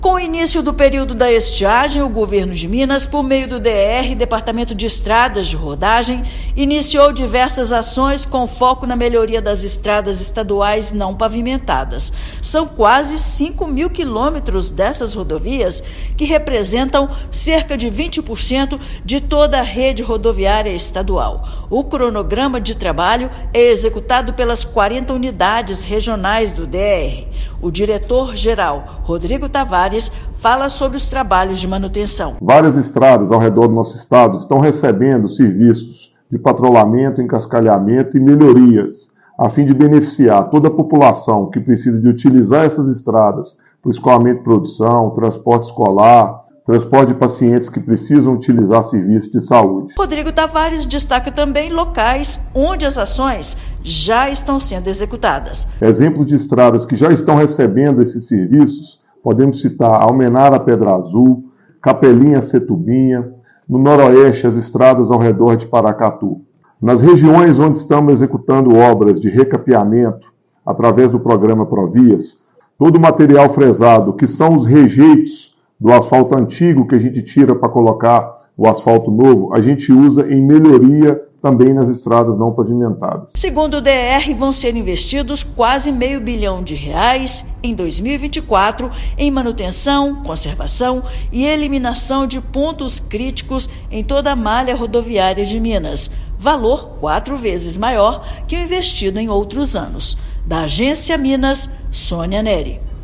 DER-MG objetiva melhorar trafegabilidade e escoamento da produção agrícola; operações são realizadas em todas as regiões e beneficiam milhares de mineiros. Ouça matéria de rádio.